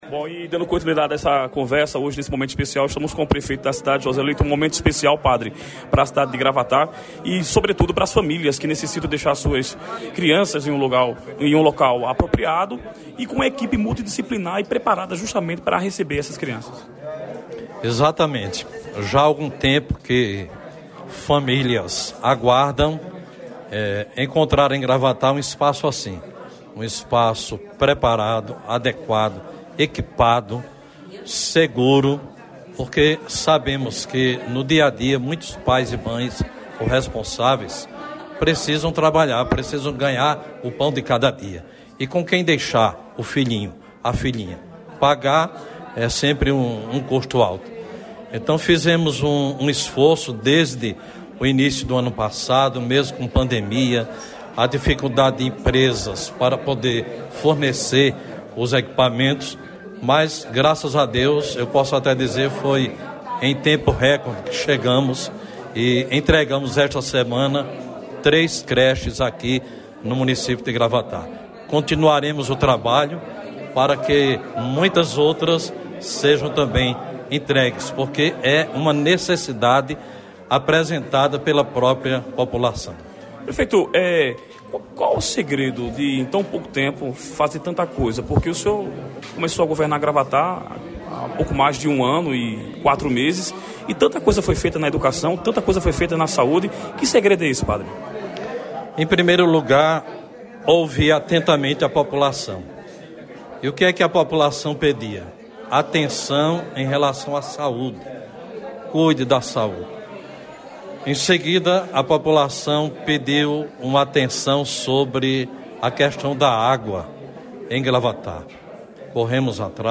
A conversa descontraída ocorreu durante a inauguração de creche nesta sexta-feira (29).